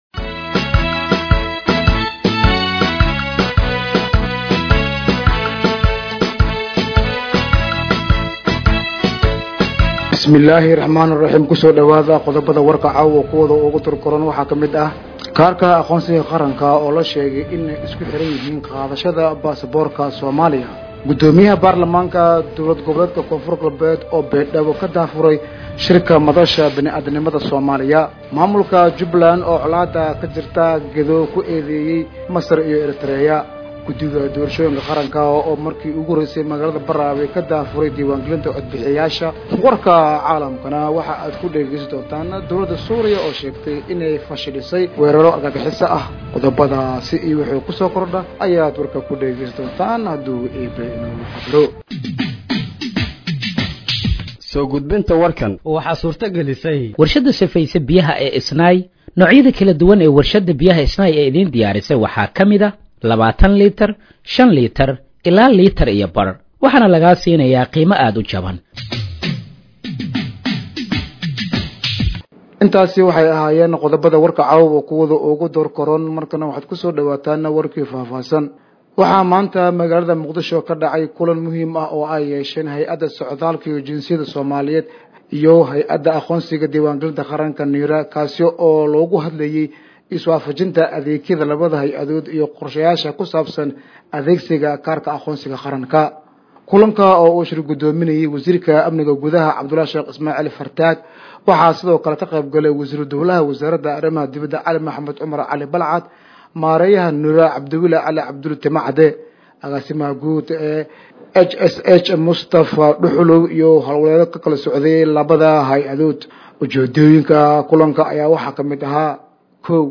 Dhageeyso Warka Habeenimo ee Radiojowhar 06/08/2025